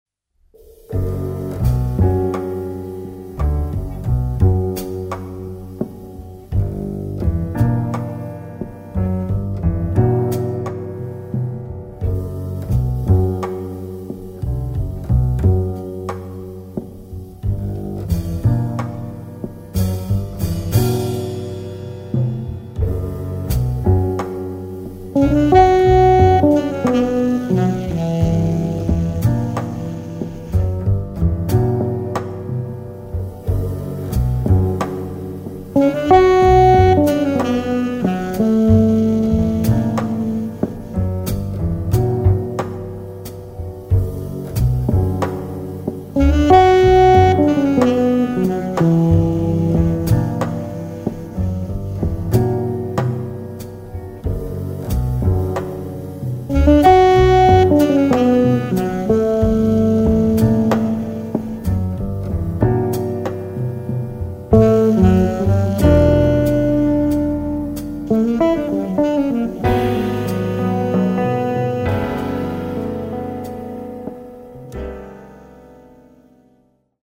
guitar
sax
piano
bass
drums